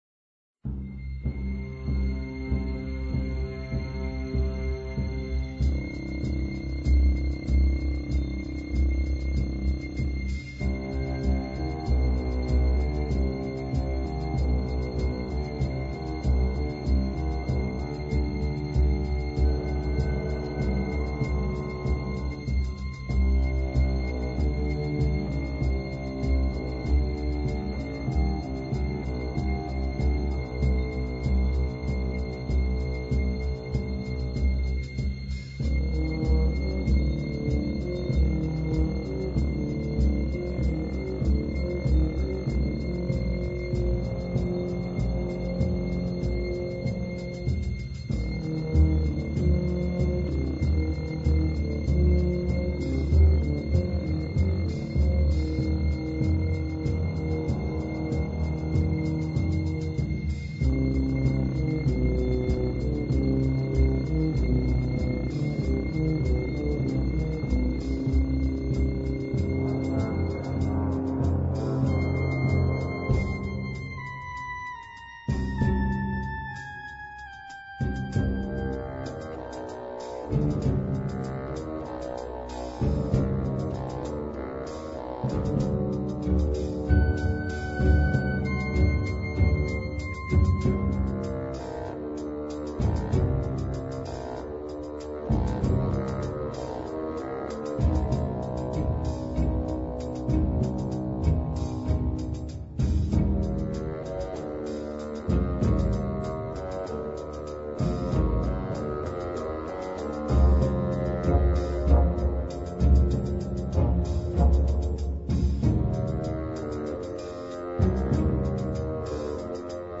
之后加入拨弦大提琴和贝斯，同时加入一些不祥的低音铜管乐器，然后断奏的弦乐开始伴随着巴松管的旋律环绕盘旋。
疯狂的第二乐句与主旋律交替显示，以此收尾。